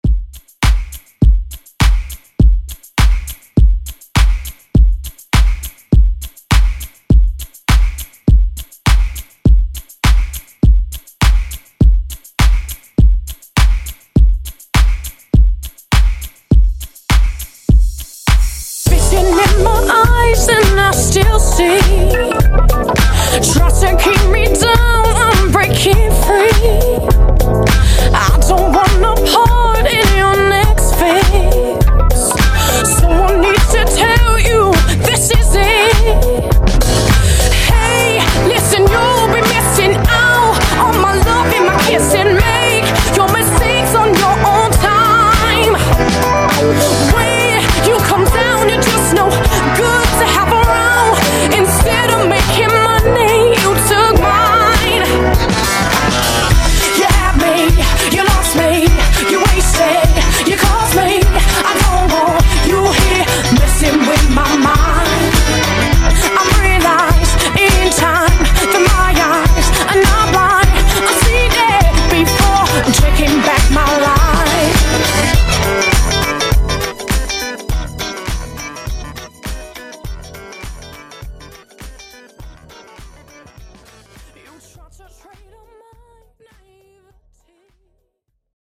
Genres: DANCE , RE-DRUM
Clean BPM: 102 Time